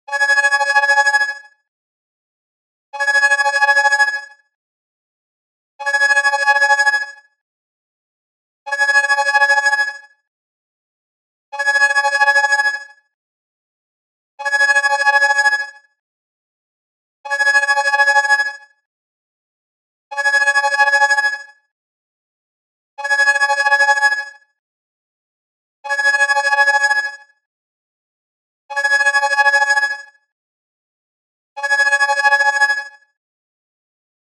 電話着信音をシンプルなものにしたい方におすすめの、ループ再生の着信音です。